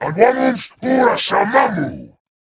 Jiralhanae voice clip from Halo: Reach.
Category:Covenant speech